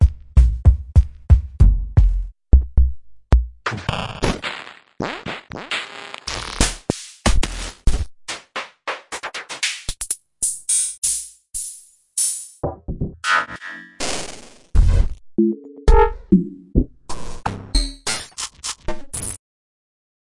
标签： 贝司 班卓琴 打击乐器